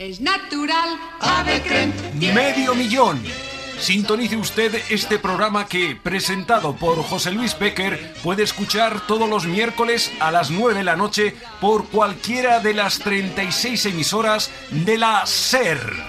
Recreació de la promoció del programa